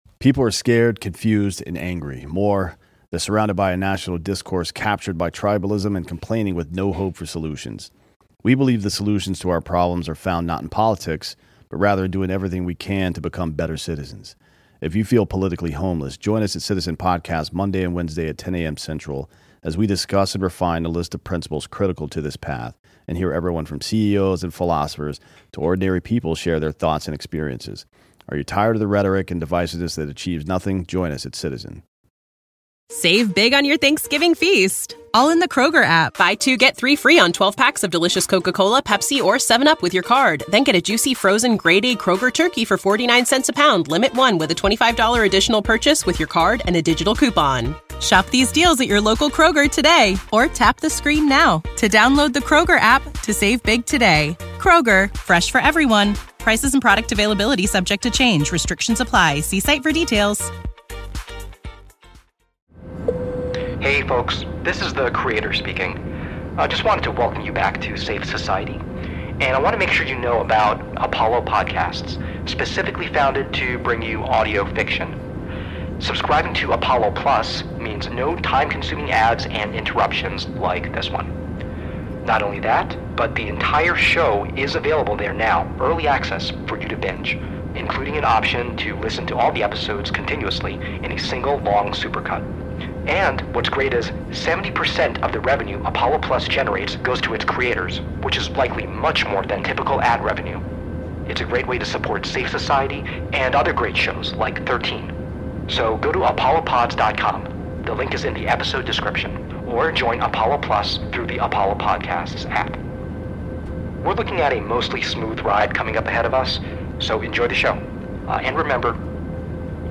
CONTENT WARNINGS Mild language, moments of unsettling and intense sound design, allusion.